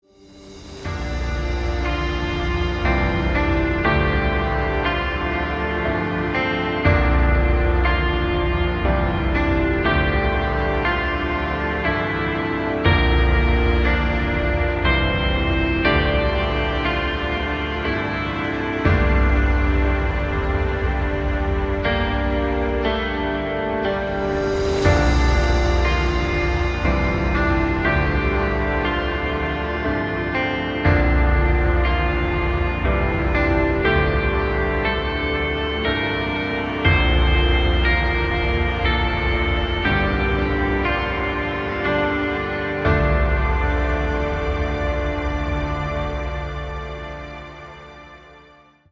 • Качество: 253, Stereo
саундтреки
спокойные
без слов
красивая мелодия
инструментальные
пианино
медленные